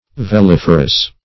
Search Result for " veliferous" : The Collaborative International Dictionary of English v.0.48: Veliferous \Ve*lif"er*ous\ (v[-e]*l[i^]f"[~e]r*[u^]s), a. [L. velifer; velum a sail + ferre to bear.] Carrying or bearing sails.